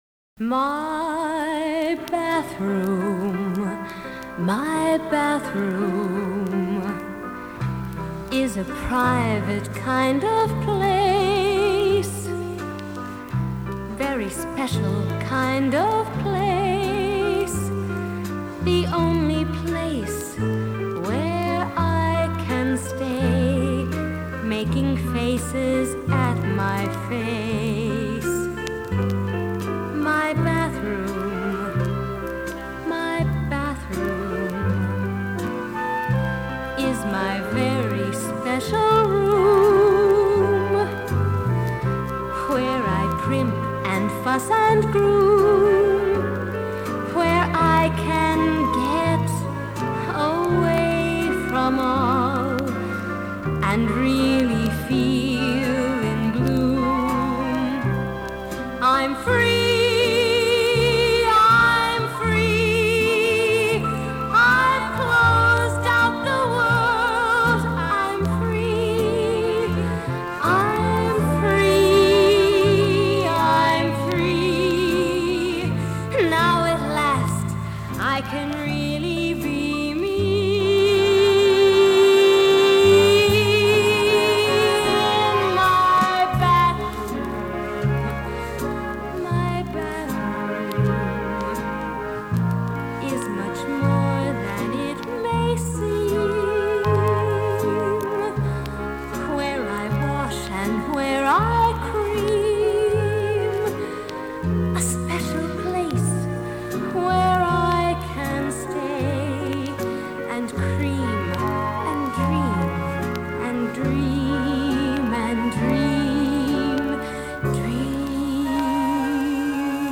an industrial-show musical
It is really well written and well sung.